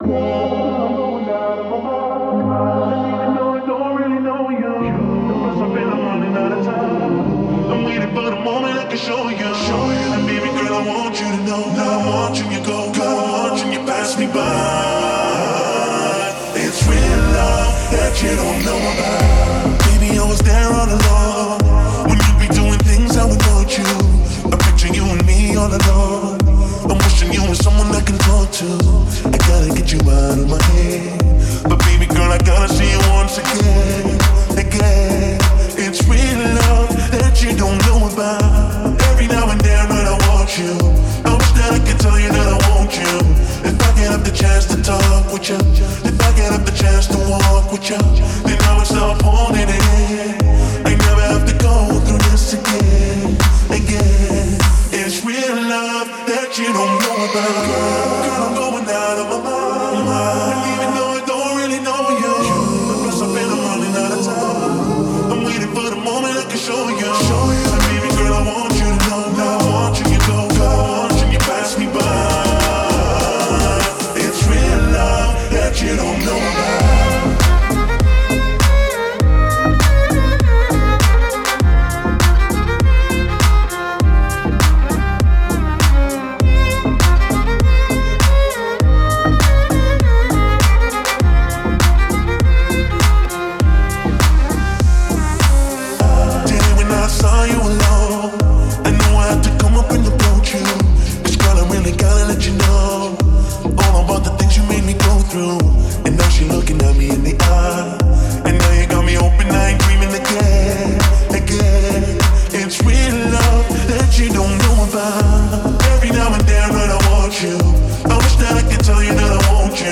это яркая и энергичная композиция в жанре R&B и поп